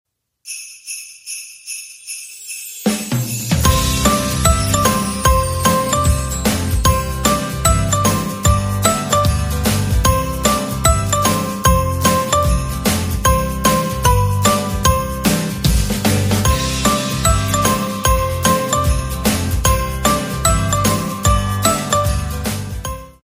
Plastic Sheet Extrusion Line | Sound Effects Free Download
Full Automatic PVC Christmas Tree Sheet Making Machine